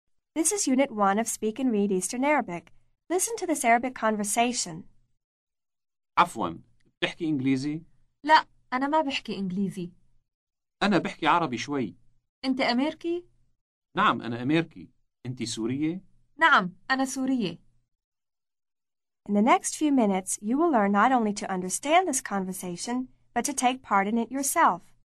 Аудио курс для самостоятельного изучения арабского языка.